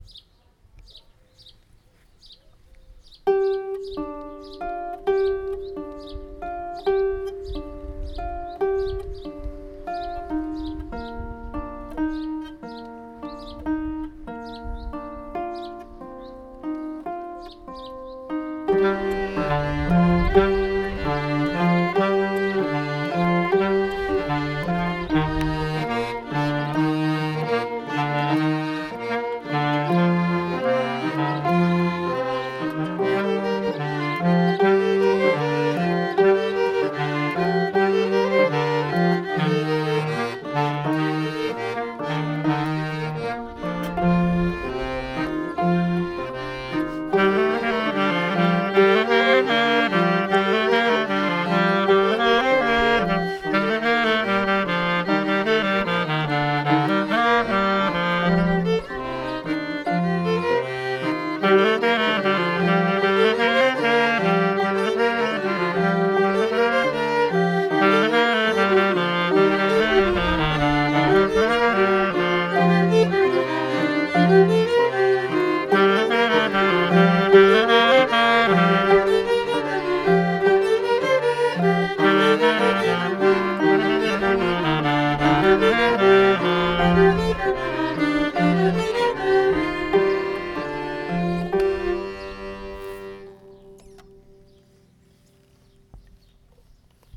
05_rond_loudia.mp3